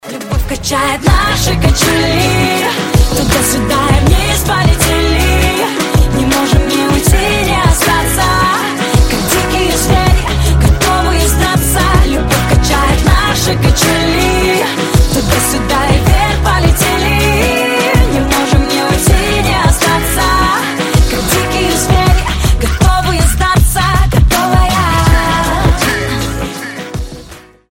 • Качество: 320, Stereo
поп
женский вокал
dance
club